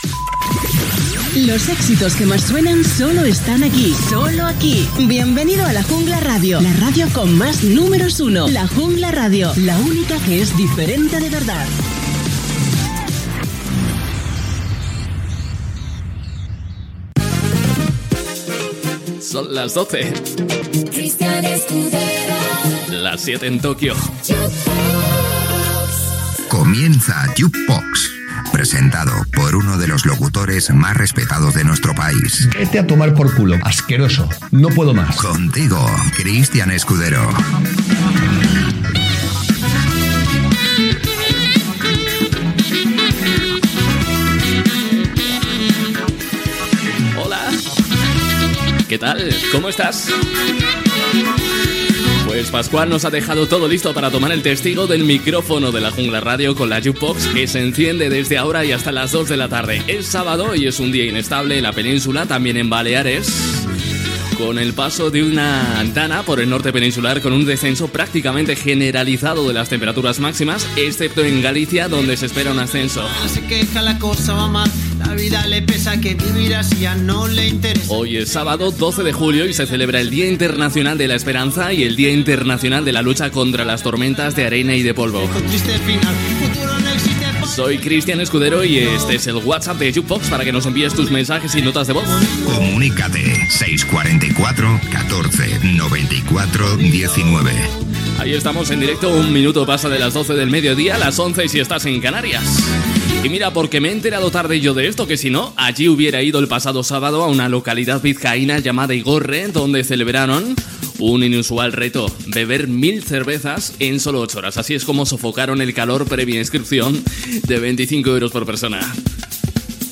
Indicatiu de la ràdio, hora, careta i inici del programa.
Musical
FM